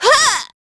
Ripine-Vox_Attack3.wav